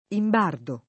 «sbandare»); imbardo [ imb # rdo ]